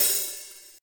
soft-hitfinish.mp3